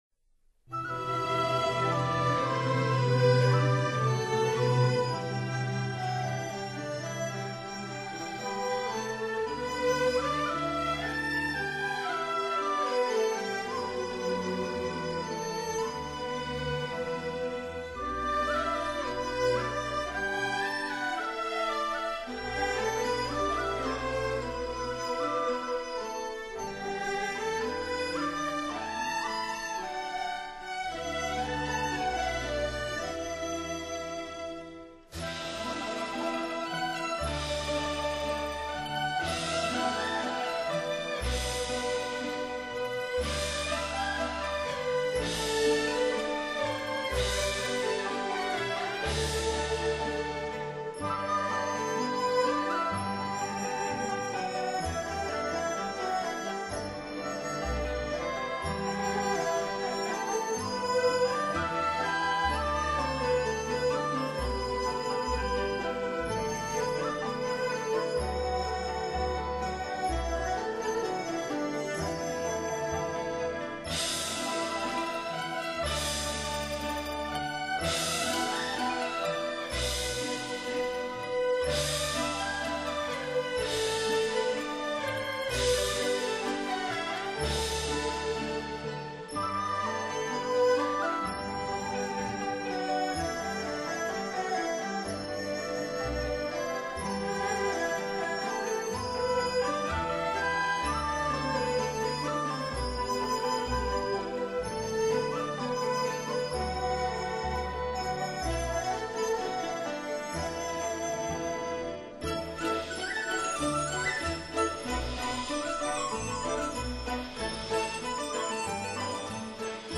流畅自然，驾轻就熟